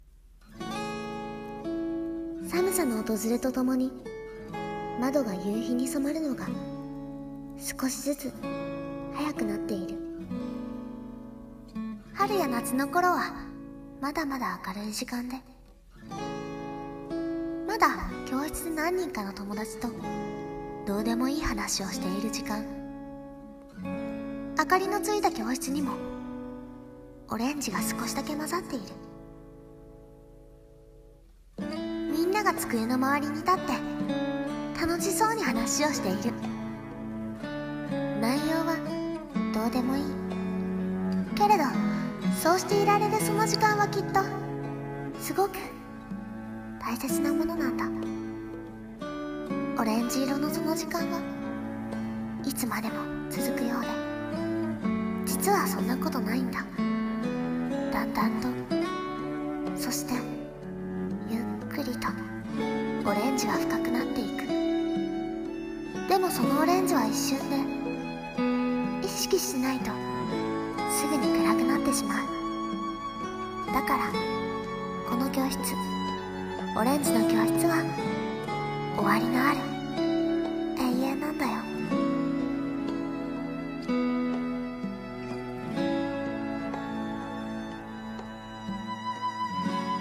【一人声劇】オレンジの教室【声劇台本】 のんのんびより より